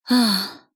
大人女性│女魔導師│リアクションボイス│商用利用可 フリーボイス素材 - freevoice4creators
ため息